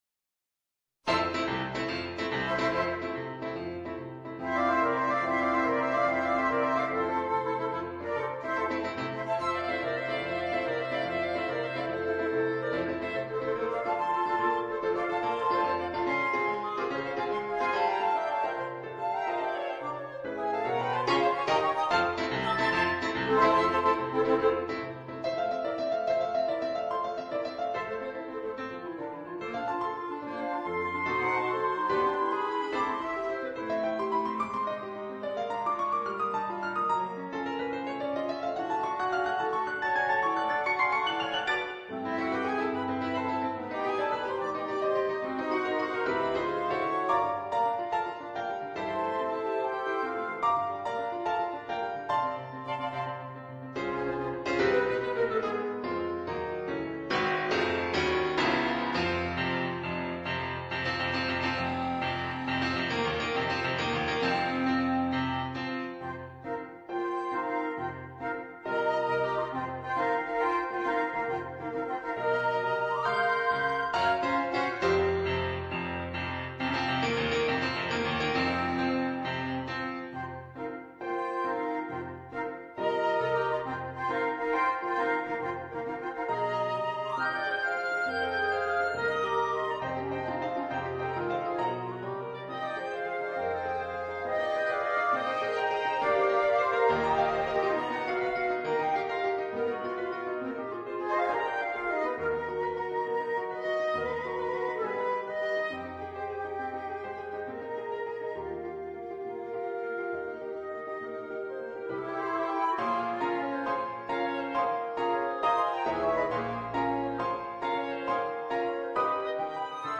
duo di clarinetti , duo di flauti , pianoforte